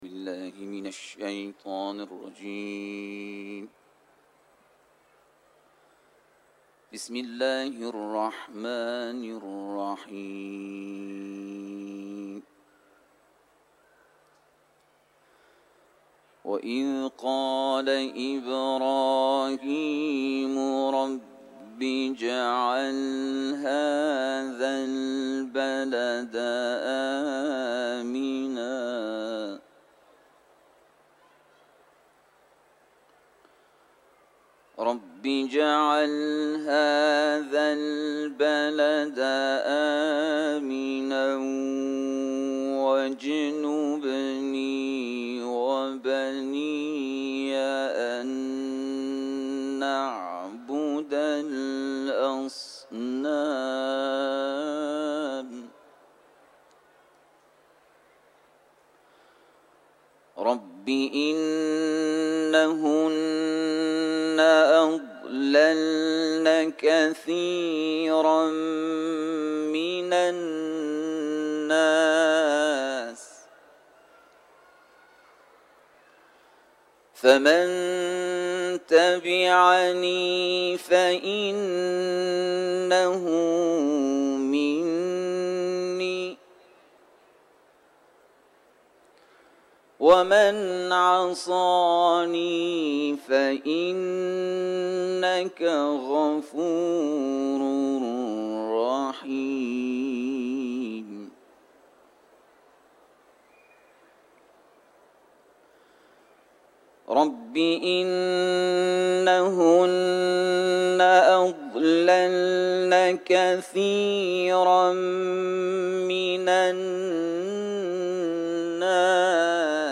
صوت | تلاوت